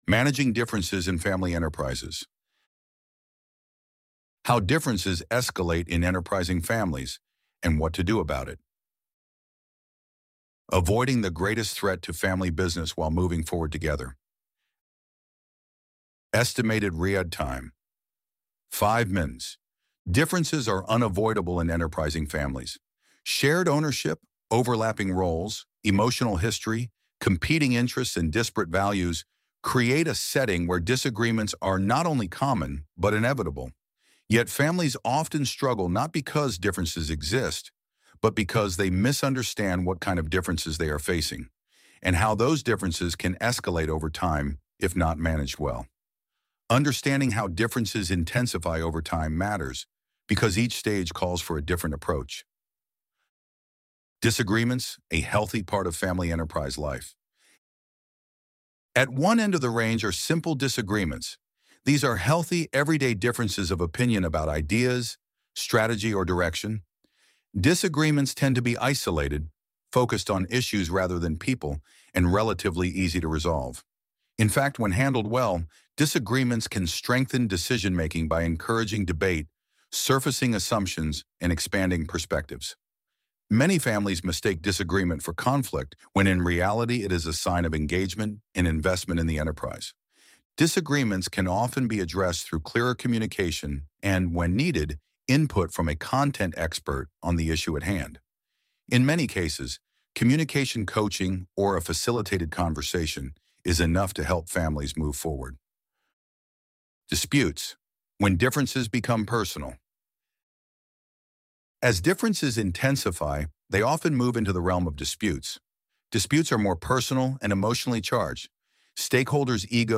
Loading the Elevenlabs Text to Speech AudioNative Player...